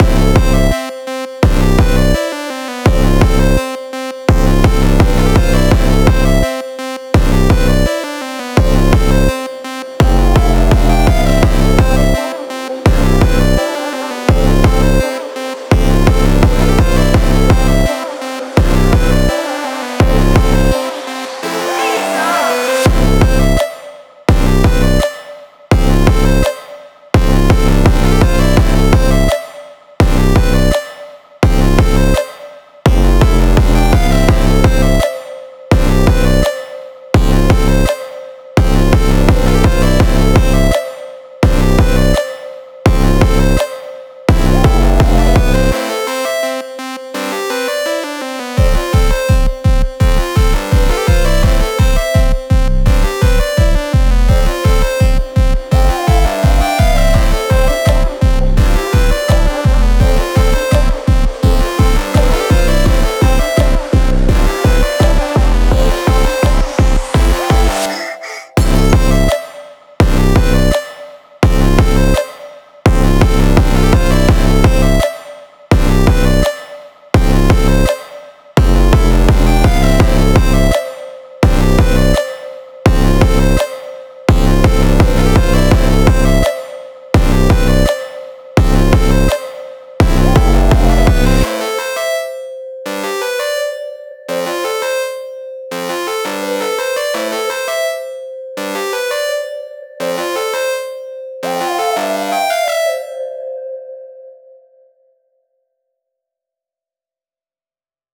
Time – (1:48)　bpm.168